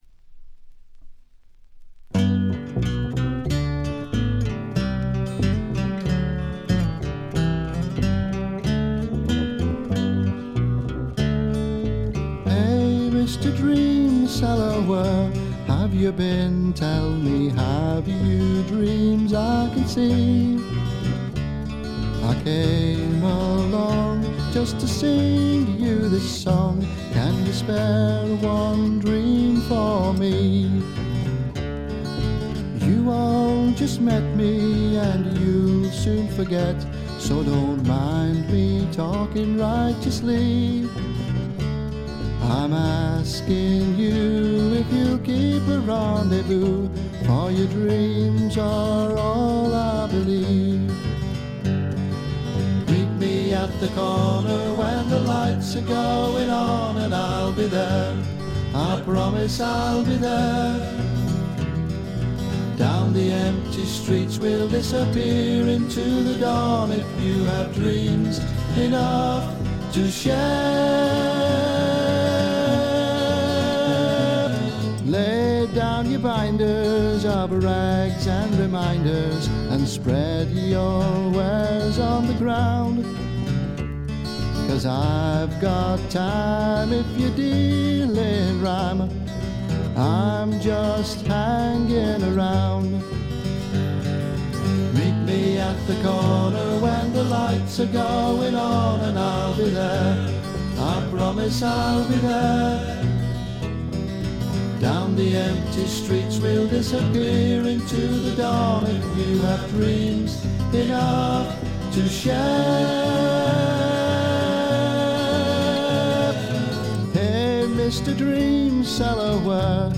ほとんどノイズ感無し。
試聴曲は現品からの取り込み音源です。